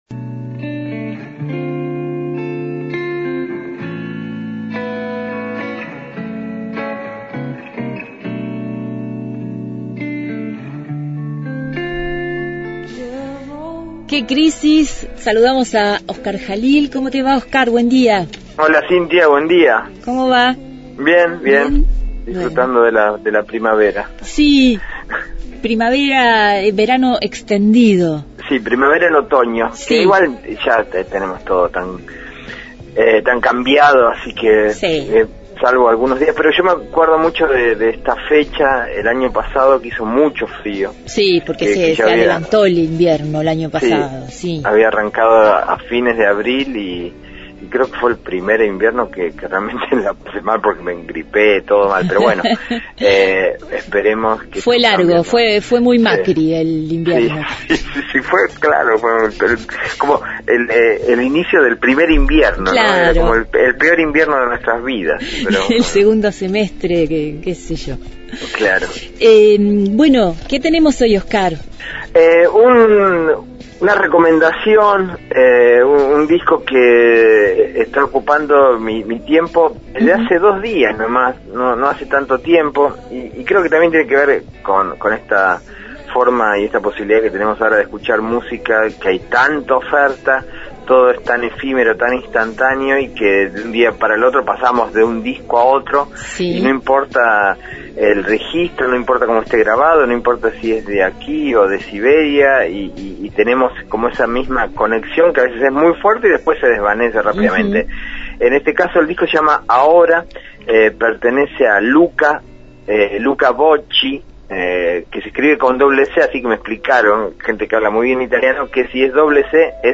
Columna musical